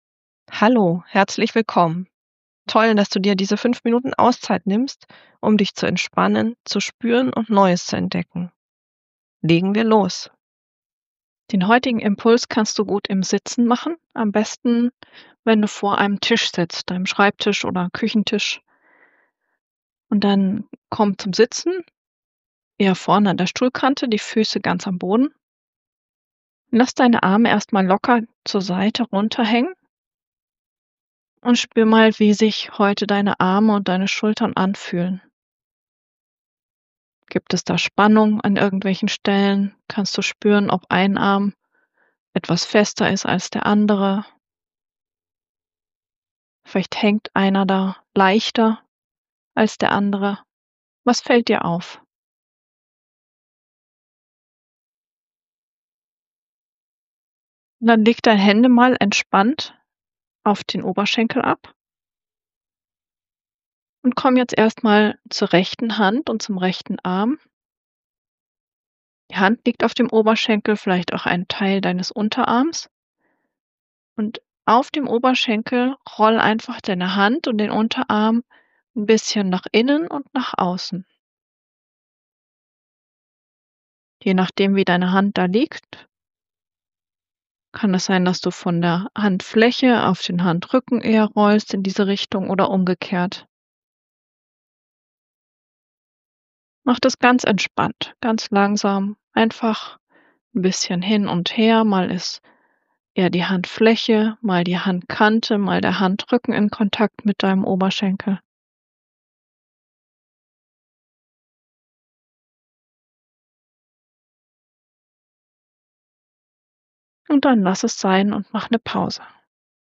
Die Bewegungsanleitungen in der Feldenkrais Methode heißen Bewusstheit durch Bewegung.